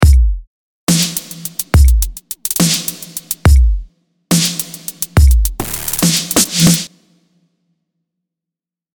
As the beat I was treating had quite a bit of space I used a medium hall.
The snares with added reverb